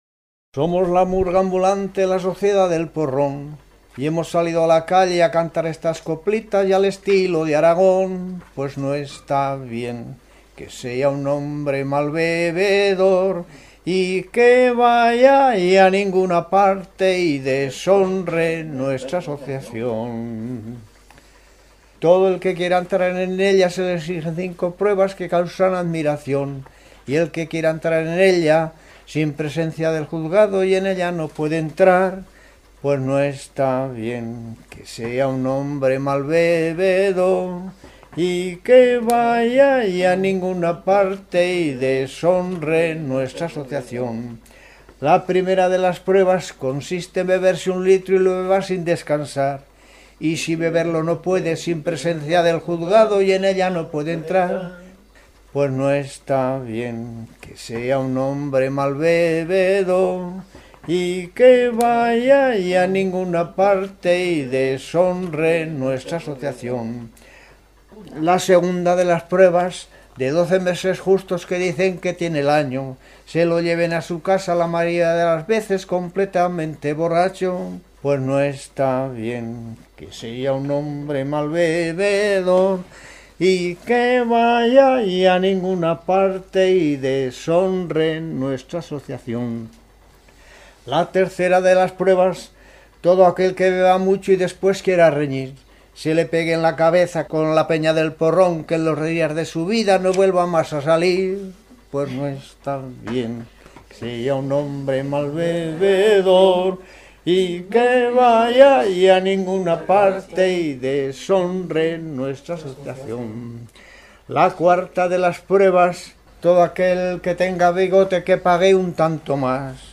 Clasificación: Brindis y cultura del vino
Lugar y fecha de recogida: Albelda de Iregua, 11 de julio de 2002